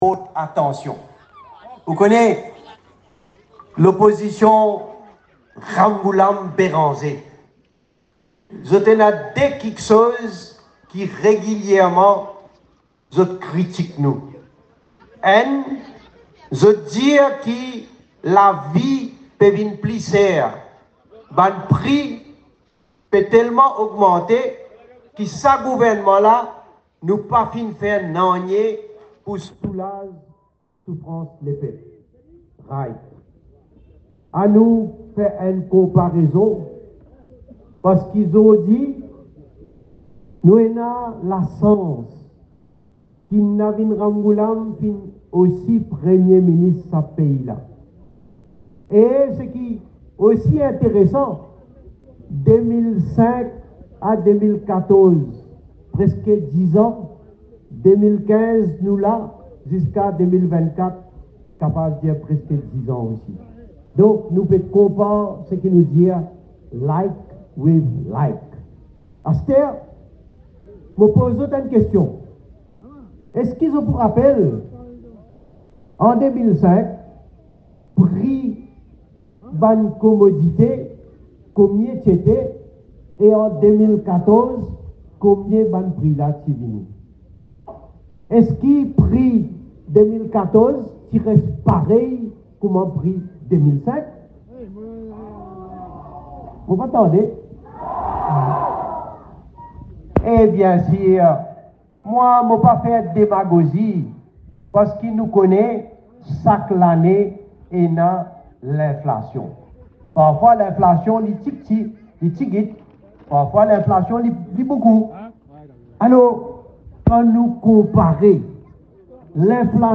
Ce dimanche 27 octobre, lors d’un meeting à Grand-Bois dans la circonscription n°13, le Premier ministre sortant, Pravind Jugnauth, a affirmé que son gouvernement a géré l'économie de manière plus efficace que ses prédécesseurs.